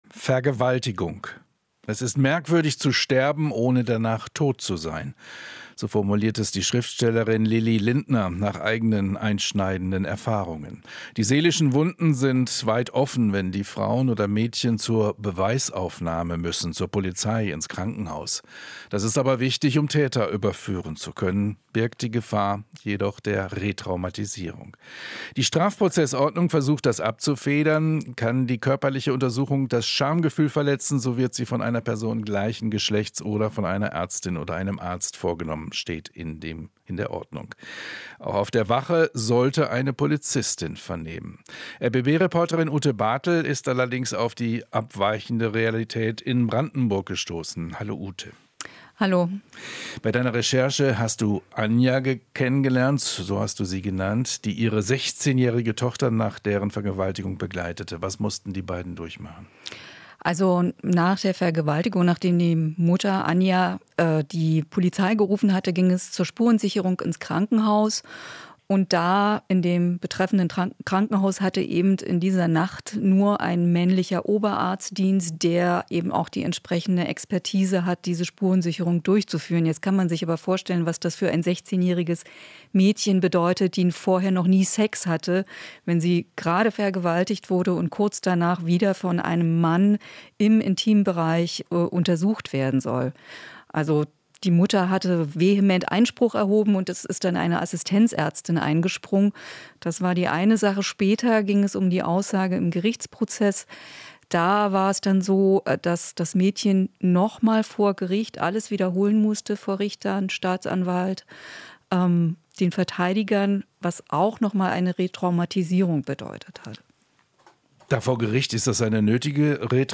Interview - Mangelnder Schutz für Vergewaltigungsopfer in Brandenburg